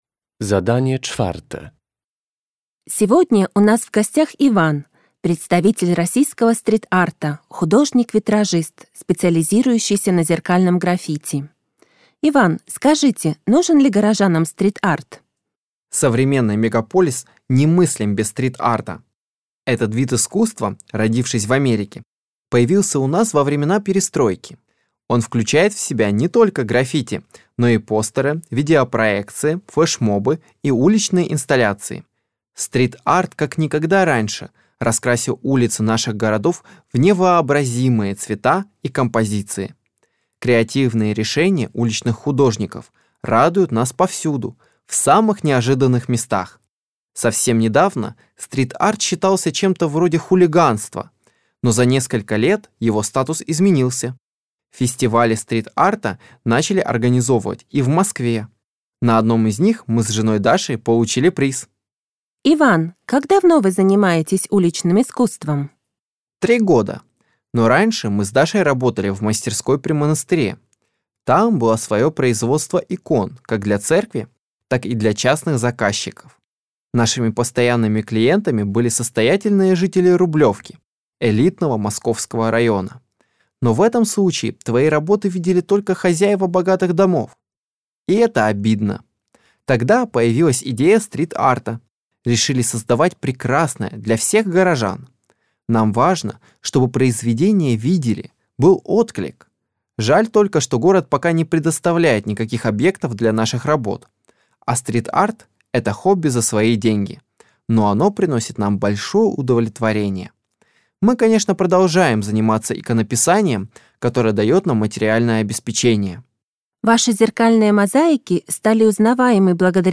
Uruchamiając odtwarzacz z oryginalnym nagraniem CKE usłyszysz dwukrotnie wywiad z młodym rosyjskim artystą.